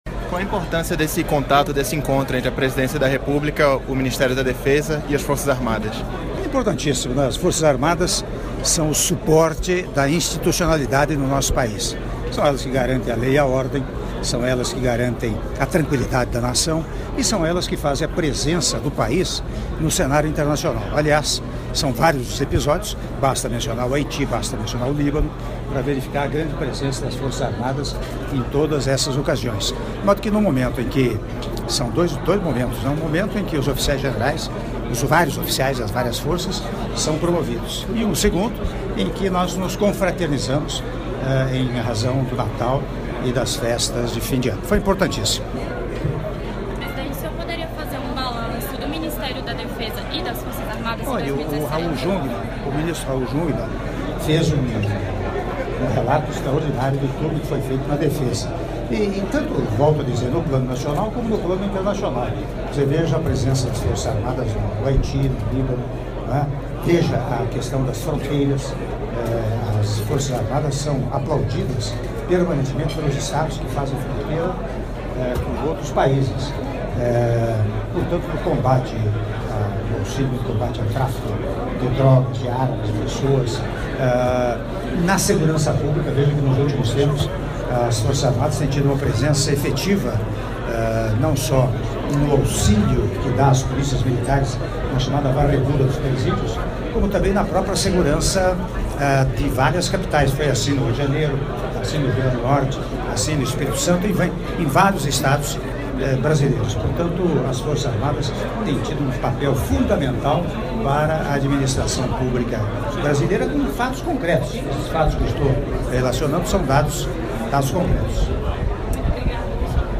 Áudio da entrevista coletiva concedida pelo Presidente da República, Michel Temer, após cerimônia de apresentação de Oficiais-Generais promovidos pelo Presidente da República, seguido de cumprimentos e almoço - Brasília/DF (02min01s)